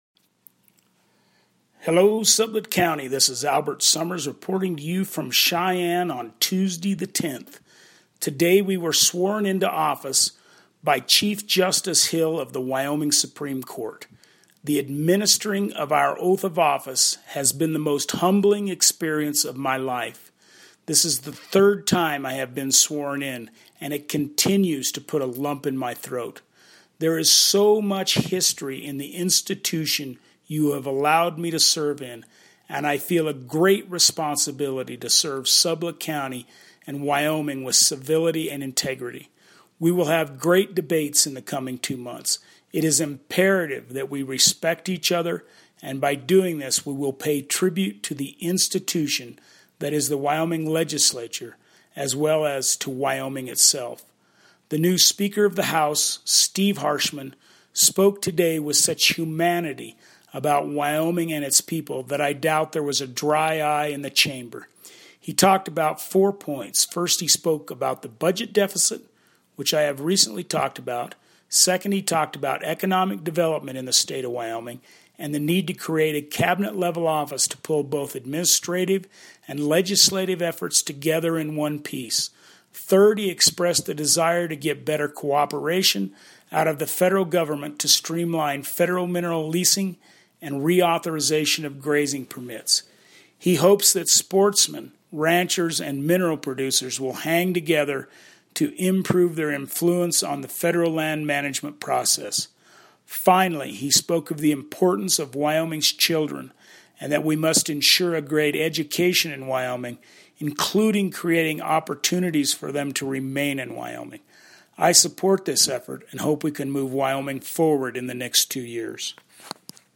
by Representative Albert Sommers, House District #20
Albert Sommers January 10, 2017 Legislature update (1.97MB mp3 audio file, 2:06 mins)